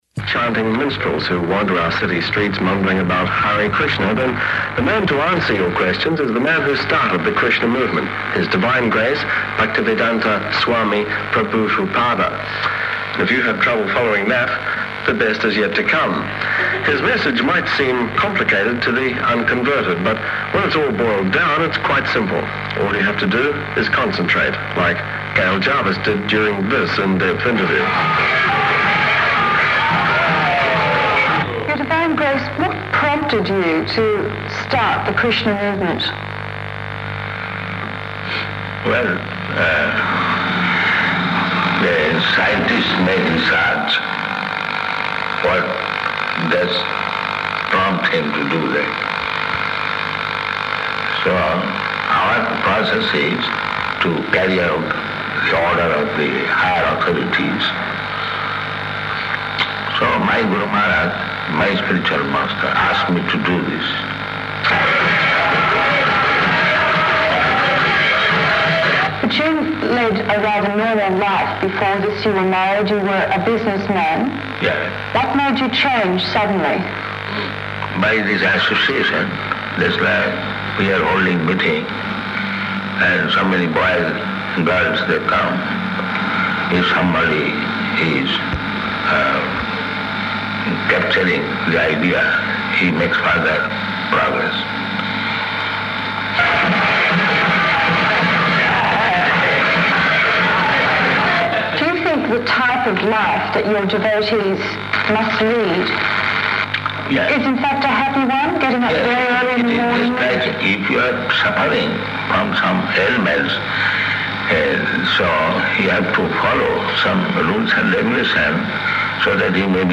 Recording of TV Interview [partially recorded]
Location: Melbourne
[The following is a recording of a radio broadcast comprised of exerpts from the full interview in the file 760420R1-MELBOURNE]